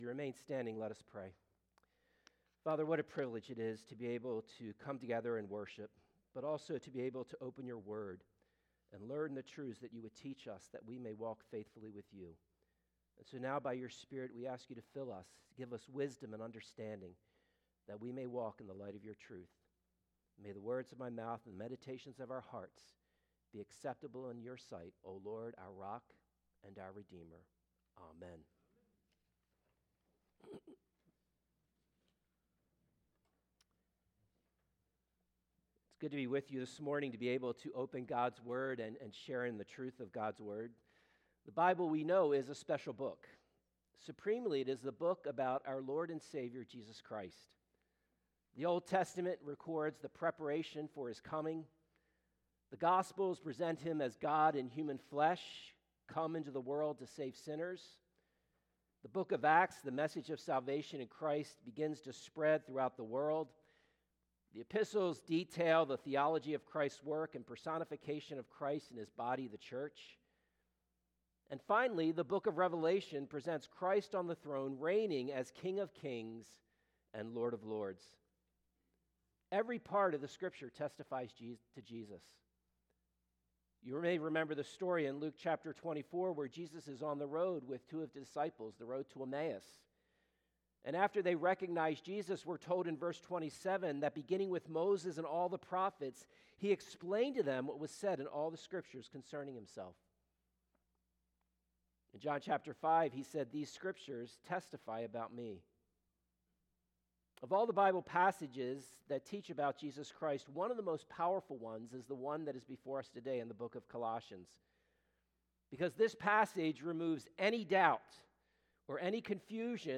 Passage: Colossians 1:15-19 Service Type: Sunday Morning « What Do We Have to Look Forward to?